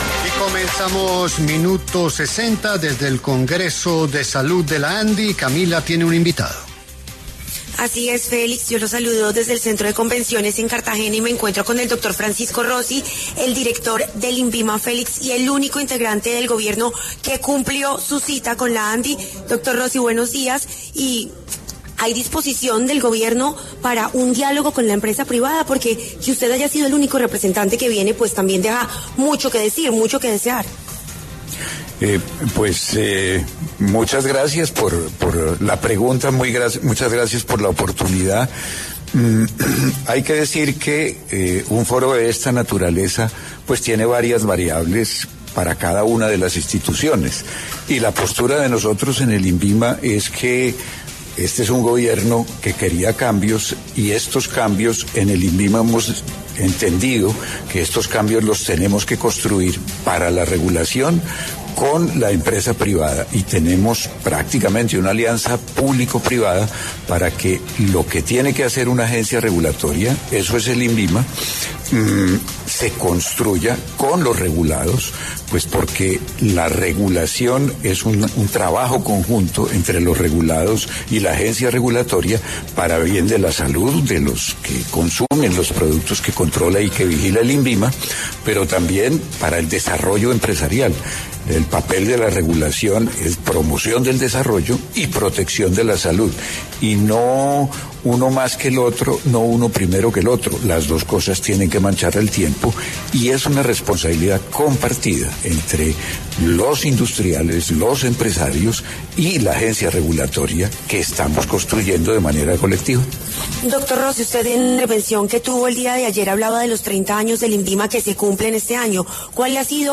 Desde el Congreso de Salud de la Asociación Nacional de Industriales (Andi), que se lleva a cabo en Cartagena, La W conversó con Francisco Rossi, director del Instituto Nacional de Vigilancia de Medicamentos y Alimentos (Invima).